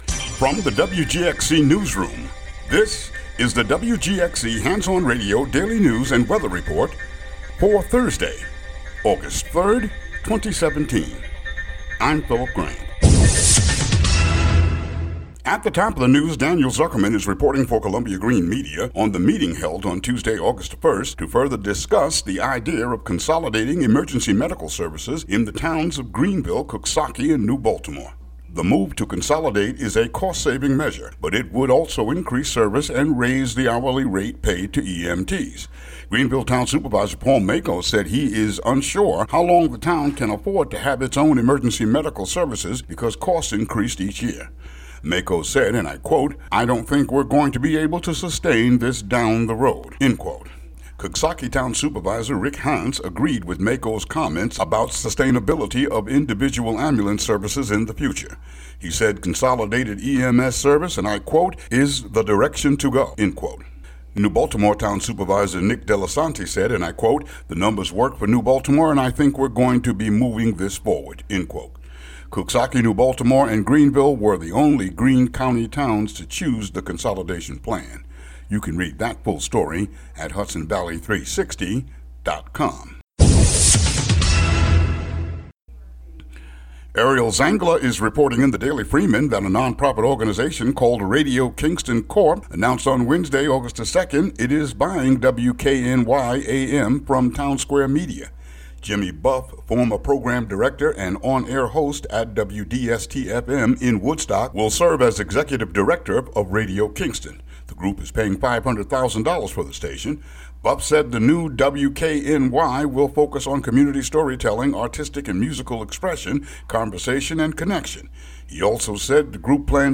WGXC daily headlines for August 3, 2017.